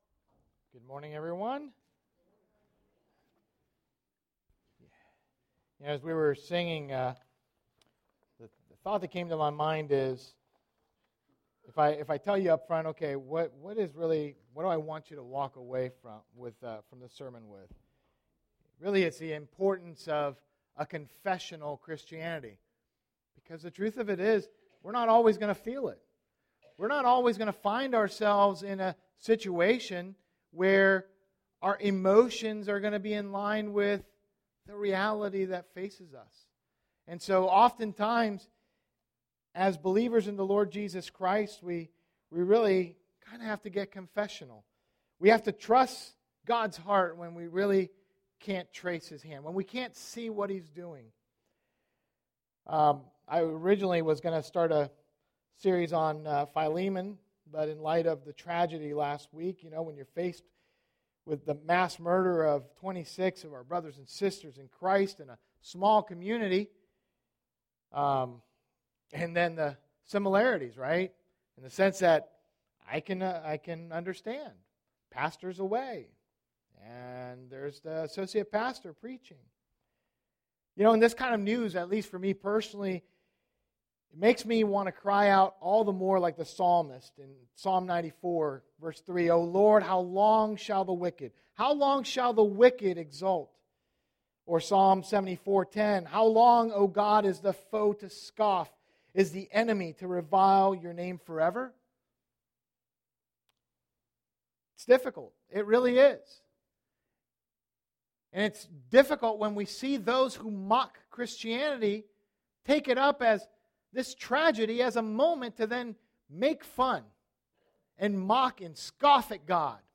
First Baptist Church of Gahanna, OH Sermons